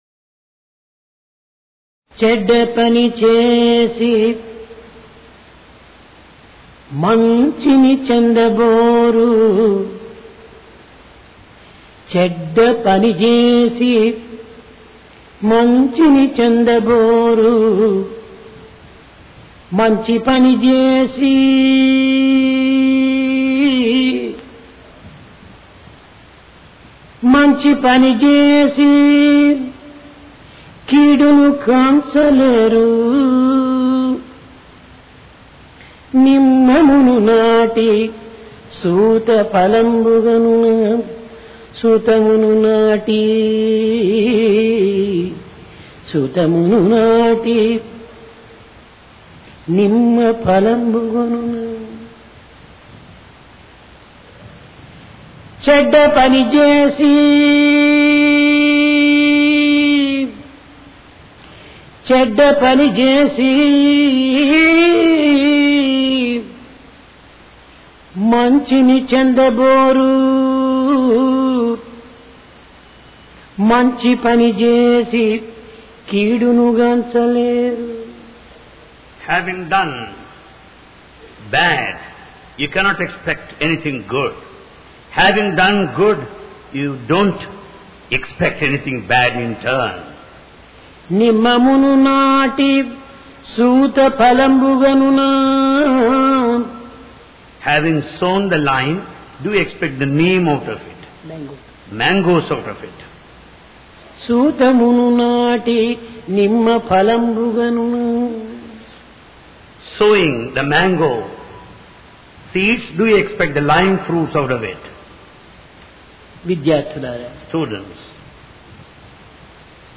PRASHANTI VAHINI - DIVINE DISCOURSE 30 JUNE, 1996